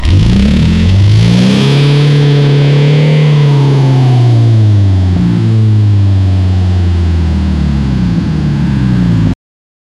range rover supercharge v8 5000cc engine sound on speed
range-rover-supercharge-v-shx33bhb.wav